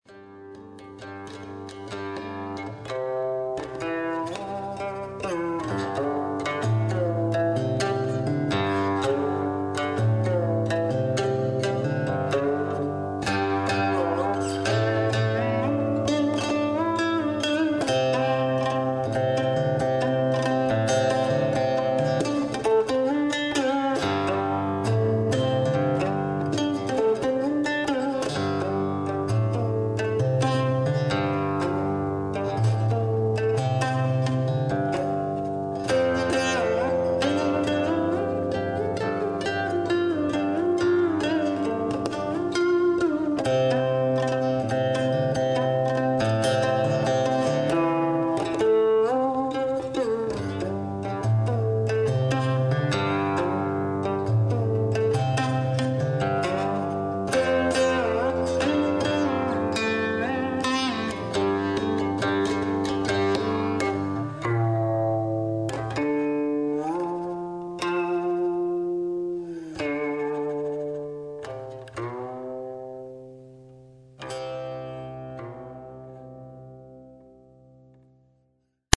Q I N   -   C H I N E S I S C H E   Z I T H E R
Qin-Musik und Poesie wurden wieder zu einer Einheit zusammengefügt und zusätzlich auf einer Studio-CD mit Begleitbuch festgehalten.
7 ausgewählte und wirklich sehr eindrücklich interpretierte alte Qin-Melodien,
play mp3 Ausschnitt  (78"/540Kb - 56Kbs/22050Khz/mono!)